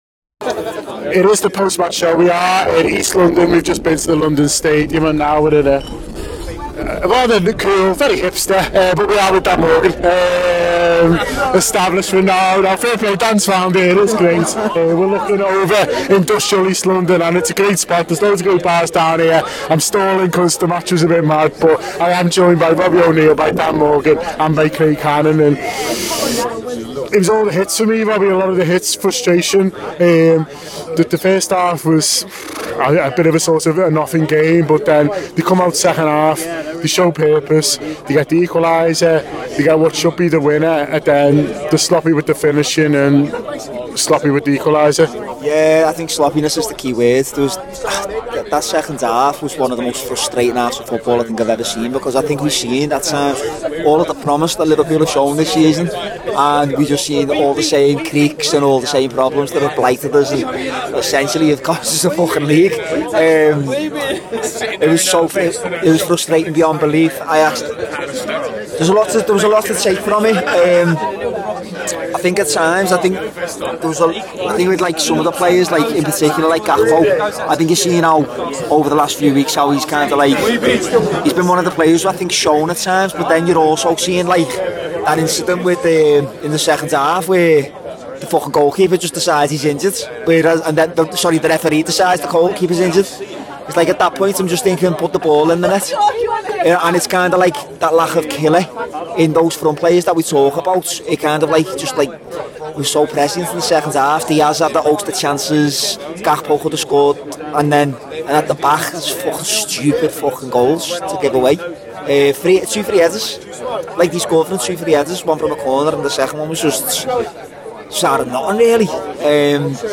The Anfield Wrap’s post-match reaction podcast after West Ham United 2 Liverpool 2 in the 2023-2024 Premier League season at the London Stadium.